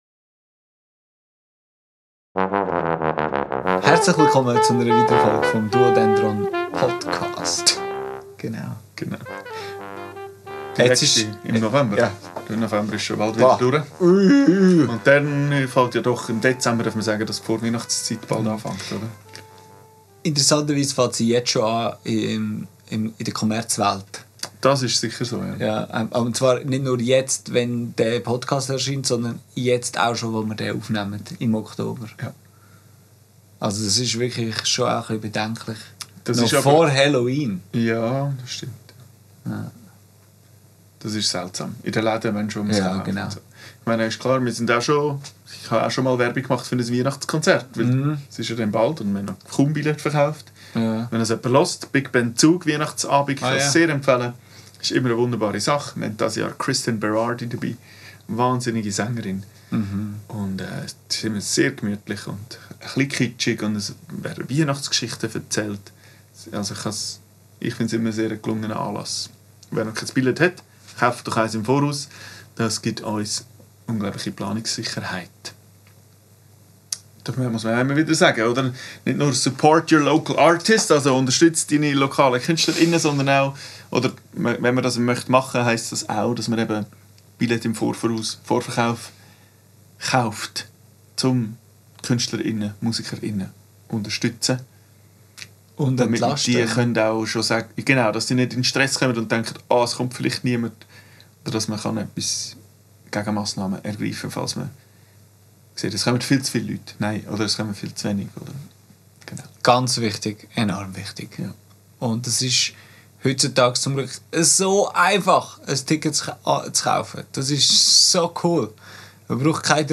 Aufgenommen am 23.10.2025 im Atelier